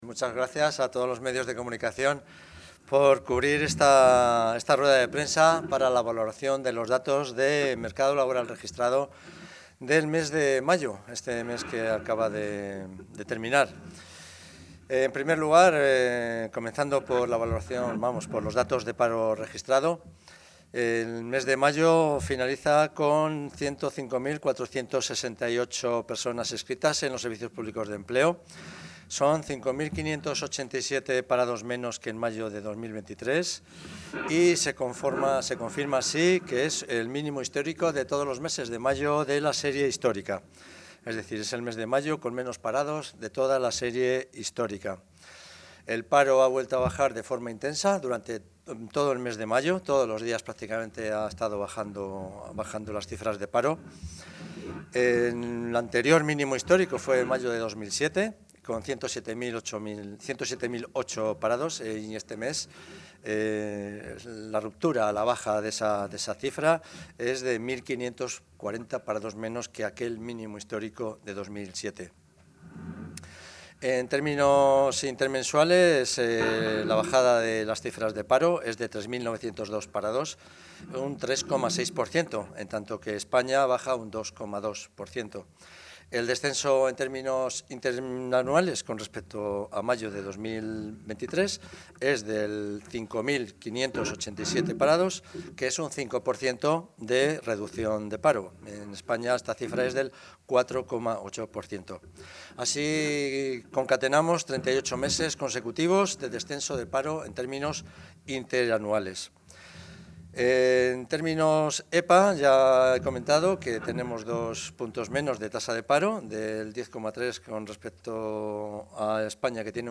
El consejero de Industria, Comercio y Empleo, Mariano Veganzones, ha valorado hoy los datos de paro registrado correspondientes al mes de mayo de 2024.